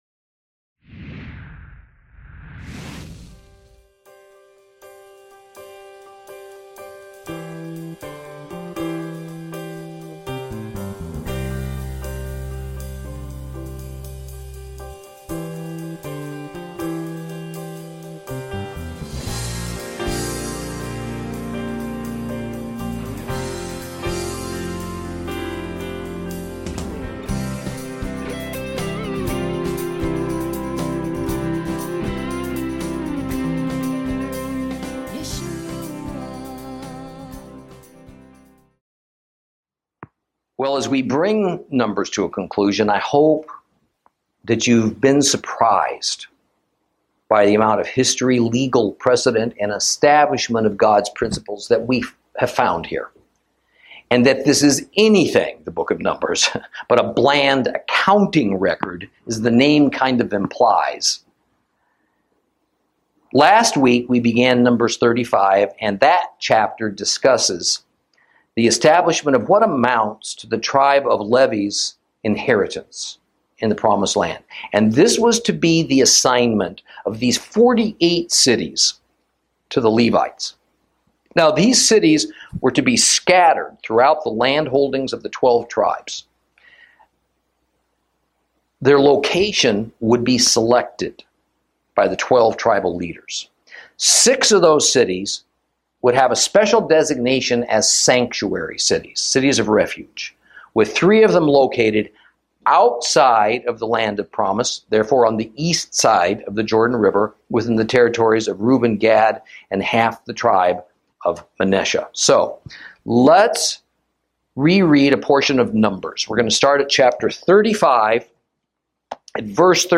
Lesson 36 Ch35 Ch36 - Torah Class